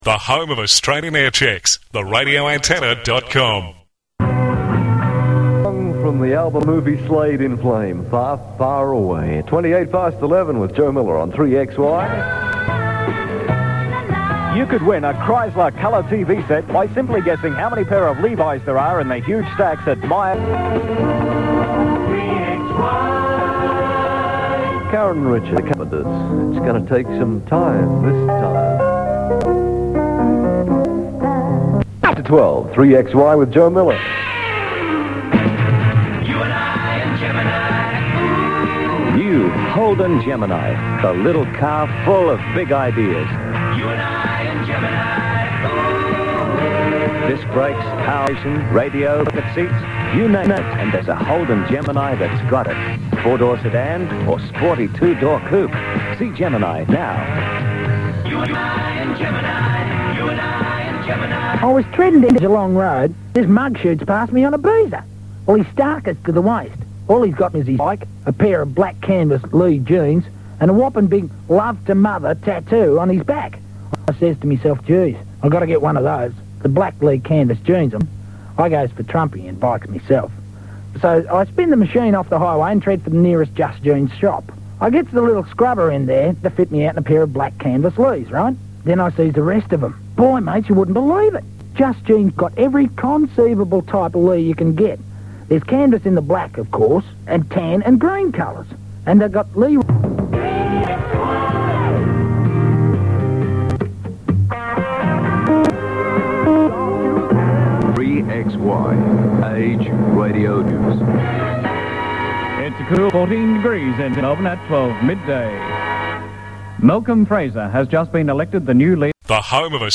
RA Aircheck